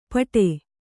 ♪ paṭe